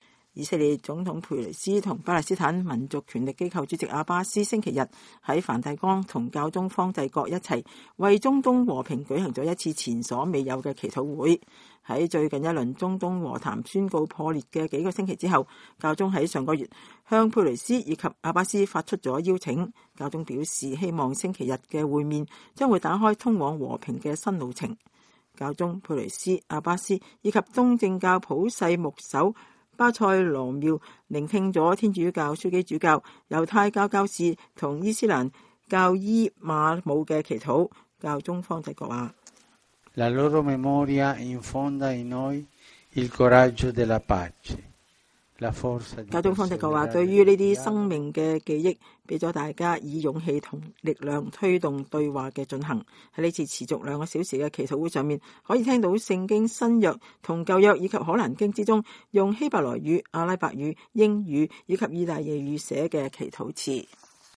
以色列總統佩雷斯和巴勒斯坦民族權力機構主席阿巴斯星期日在梵蒂岡與教宗方濟各一道，為中東和平舉行了一次前所未有的祈禱會。
在這次持續兩小時的祈禱會上，可以聽到聖經新約和舊約以及可蘭經中用希伯萊語、阿拉伯語、英語和意大利語寫的祈禱詞。